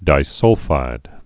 (dī-sŭlfīd)